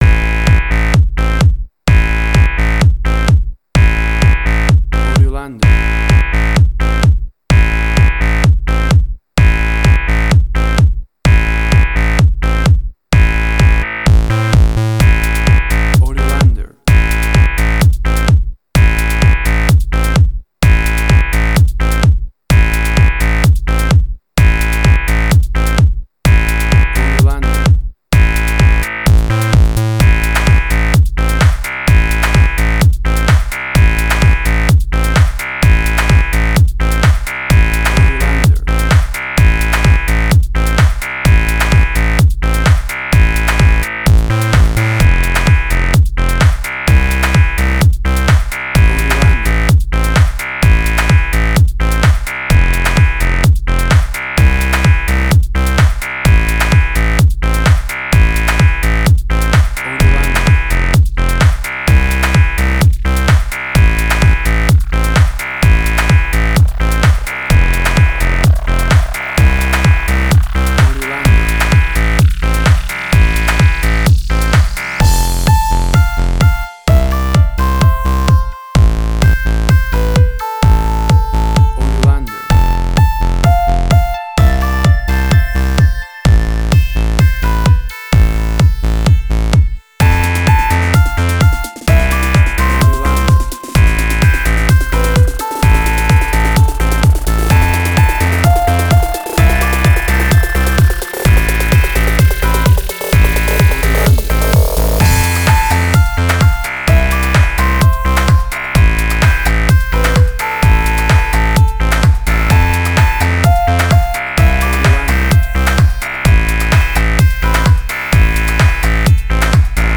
House.
Tempo (BPM): 128